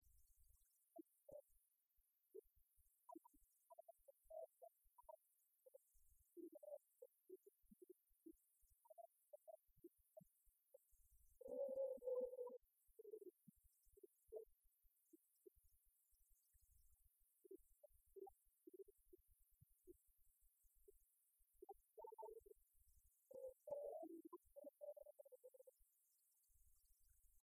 sur un timbre
Concert de la chorale des retraités
Pièce musicale inédite